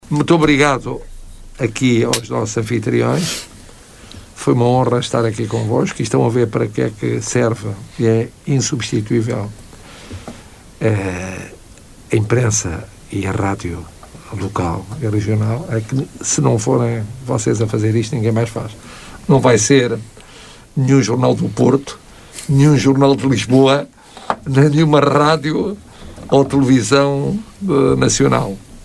Marcelo Rebelo de Sousa no final de uma conversa de mais de 45 minutos aos microfones da Rádio Caminha.